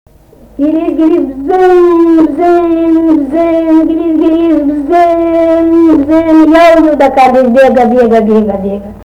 vokalinis